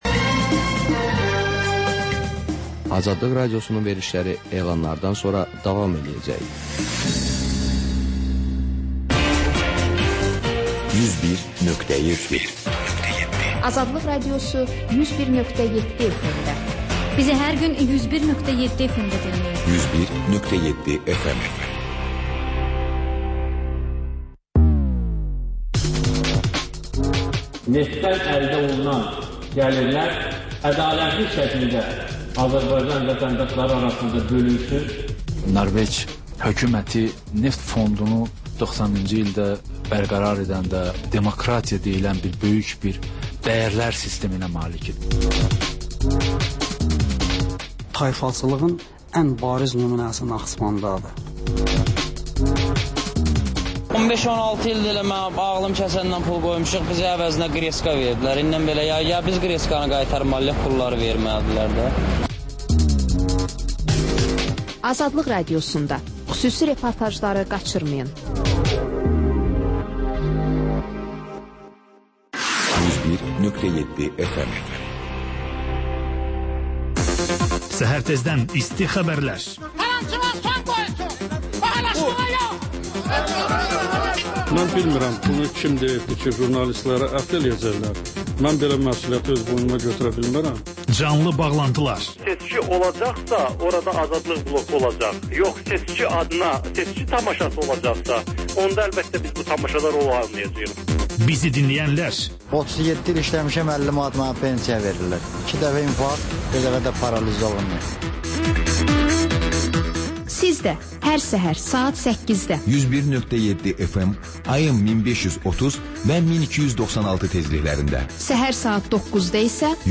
Xəbərlər, sonra CAN BAKI: Bakının ictimai və mədəni yaşamı, düşüncə və əyləncə həyatı…